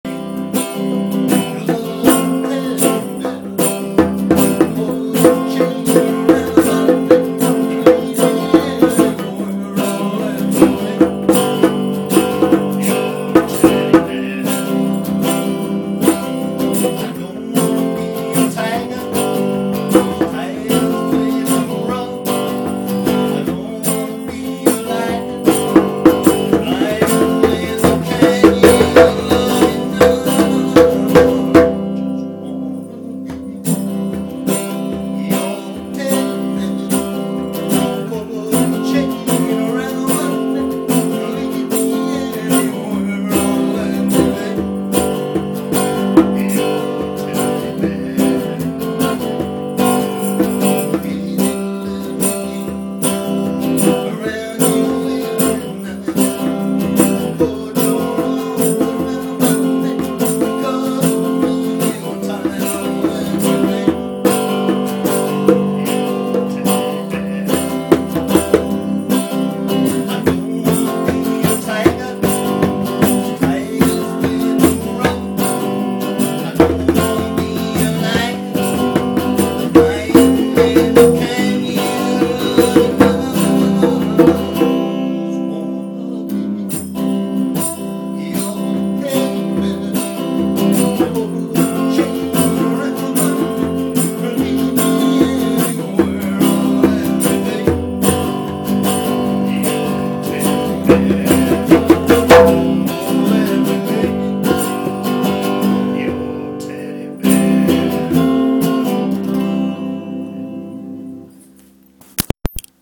Last night the front bar of the Pigs Arms was treated to a sneak preview of the Burnside Refugees latest jam session.
Anyway, here they are, jamming together for the first time in over a year, a big hand please, folks, for the BURNSIDE REFUGEES!